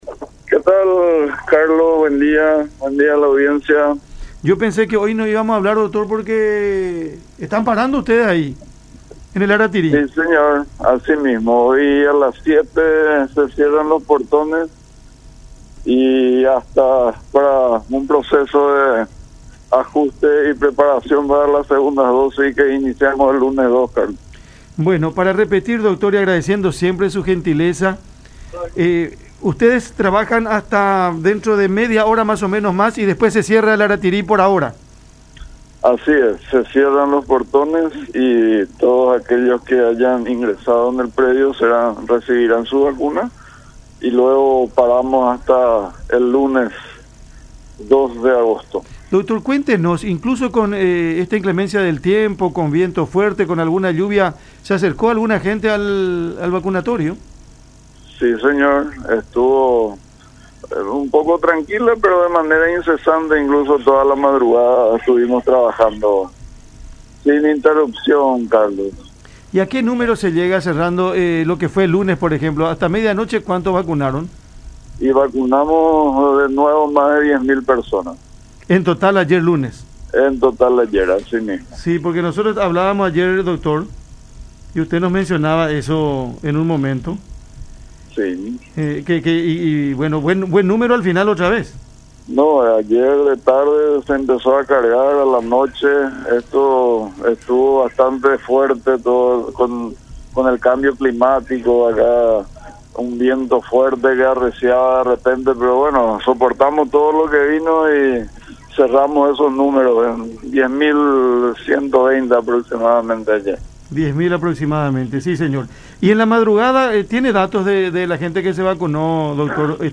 en conversación con Cada Mañana por La Unión.